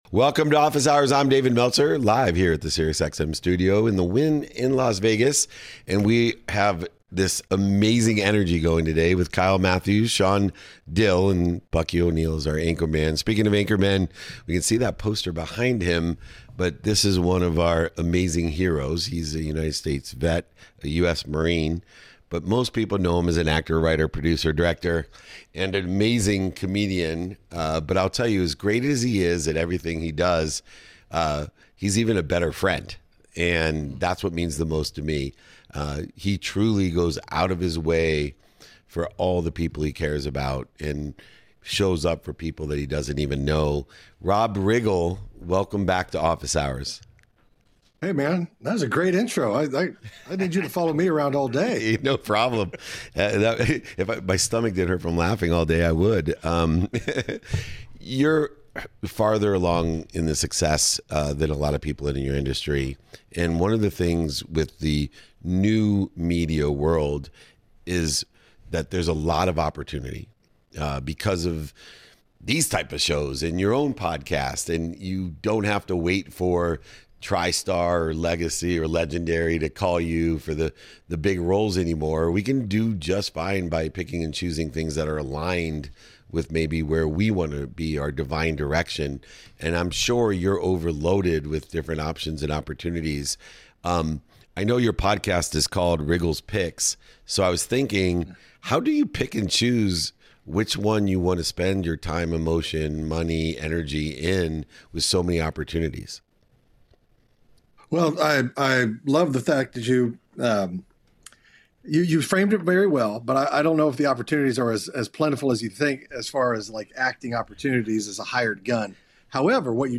In today’s episode, I sit down with Rob Riggle, a Marine Corps veteran turned actor, comedian, writer, and producer whose career spans from Saturday Night Live and The Daily Show to blockbuster films.